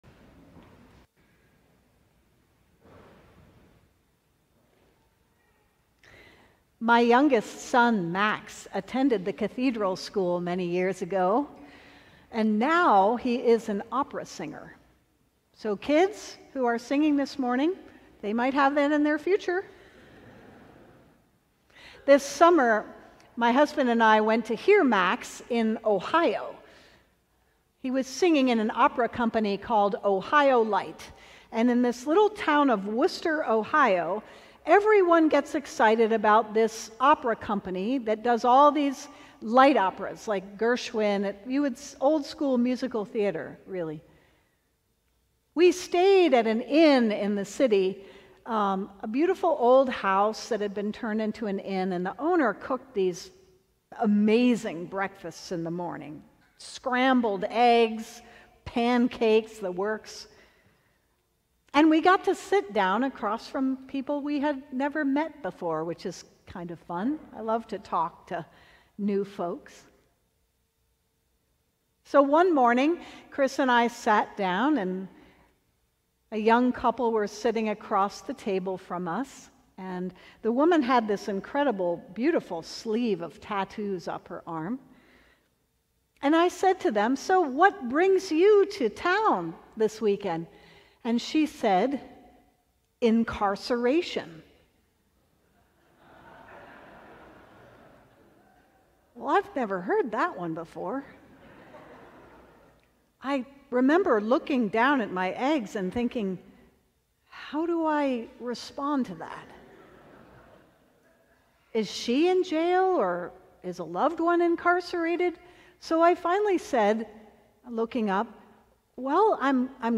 Sermon: Your New Life